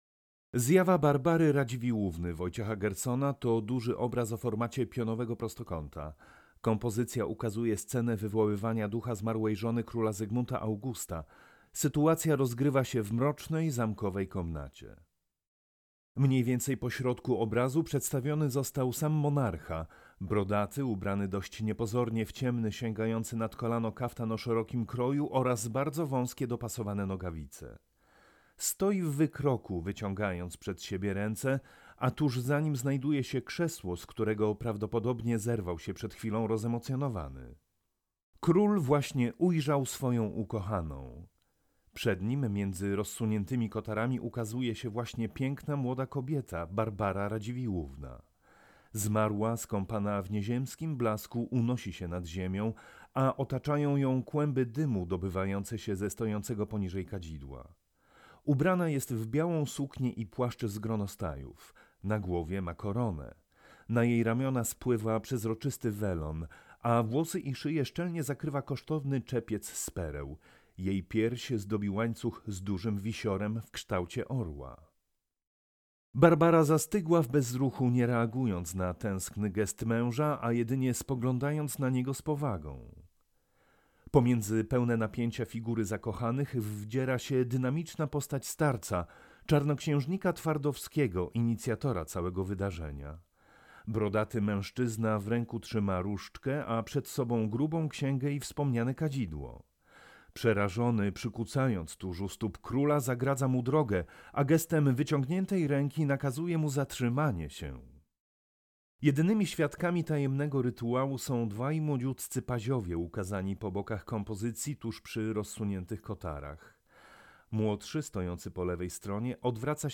AUDIODESKRYPCJA
AUDIODESKRYPCJA-Wojciech-Gerson-Zjawa-Barbary-Radziwillowny.mp3